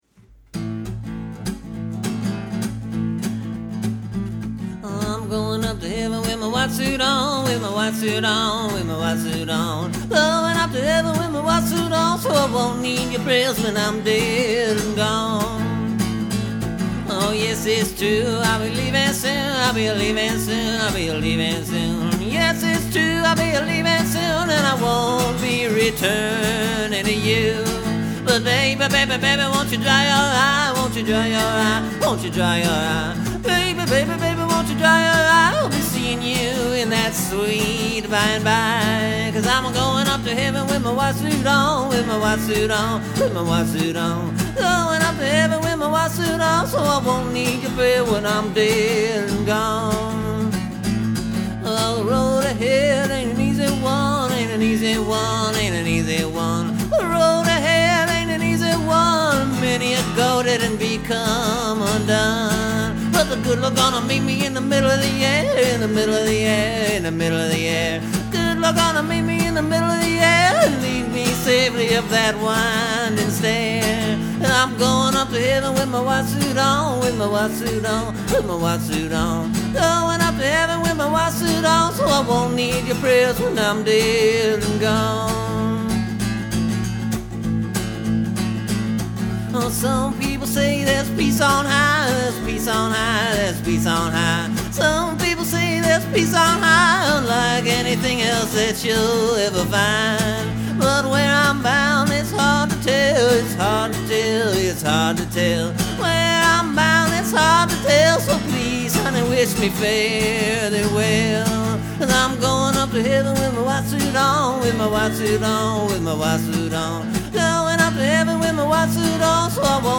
This one’s kinda more a gospel tune, I supposed, although, I’m not entirely sure what it’s all about.